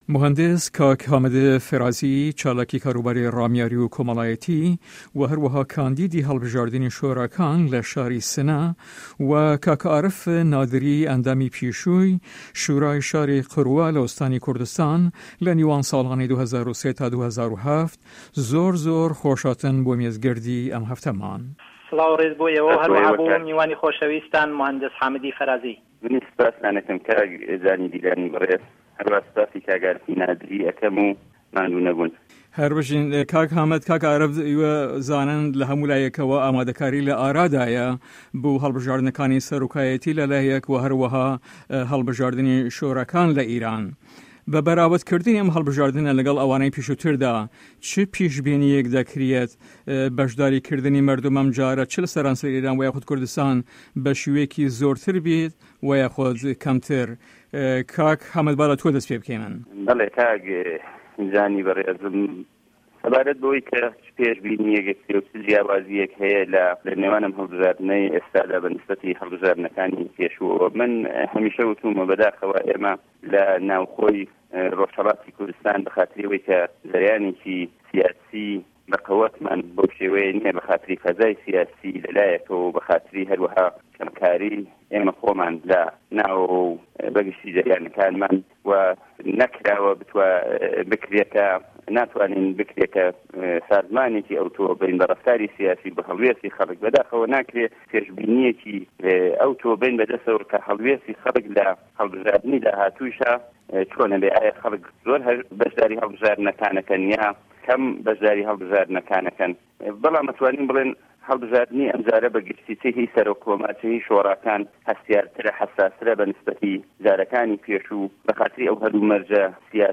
ROUND TABLE